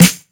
• Clean Snare Sample F Key 303.wav
Royality free steel snare drum sample tuned to the F note. Loudest frequency: 2944Hz
clean-snare-sample-f-key-303-TKX.wav